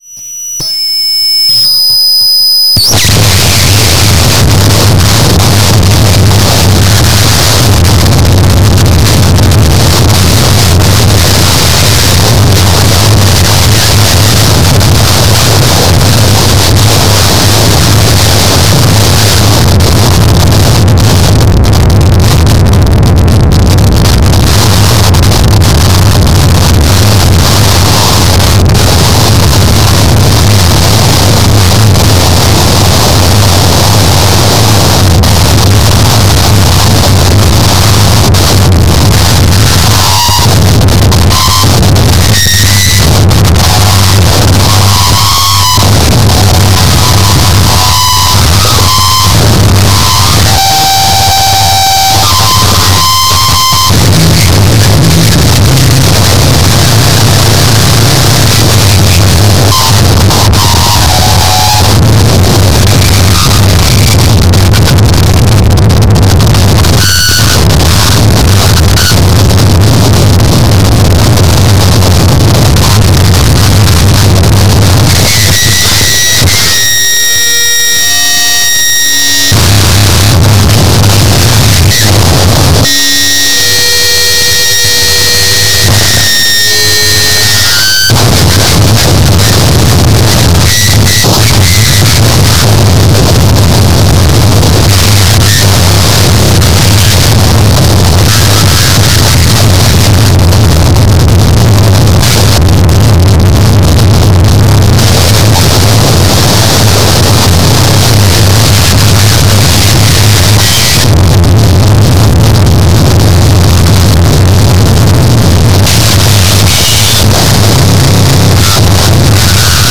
fuck you Harsh Noise and Power Electronics.